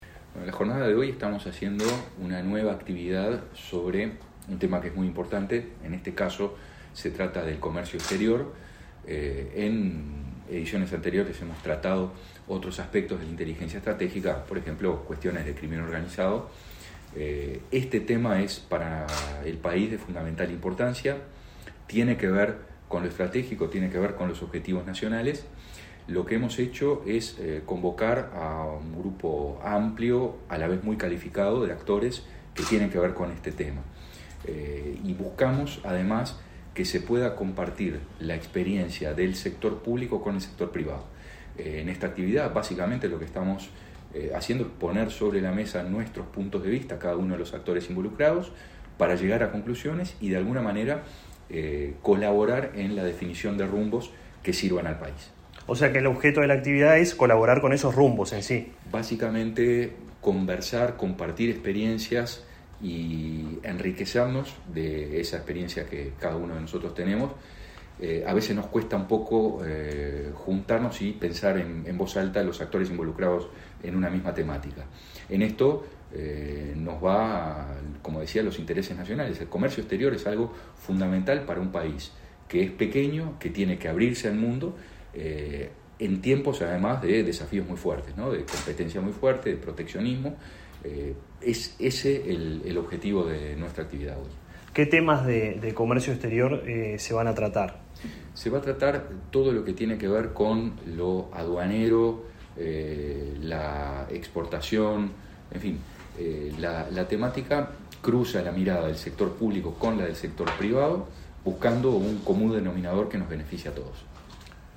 Entrevista al director de Secretaría de Inteligencia Estratégica de Estado, Álvaro Garcé